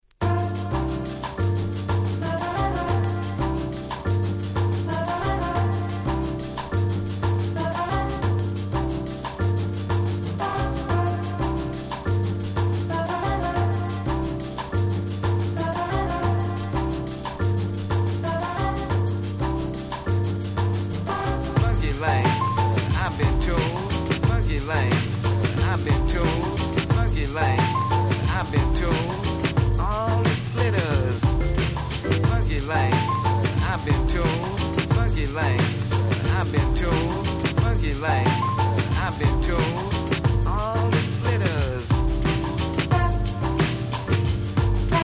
95' Crossover Hit !!
90's キャッチー系